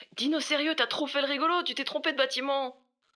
VO_LVL1_EVENT_Mauvais batiment_03.ogg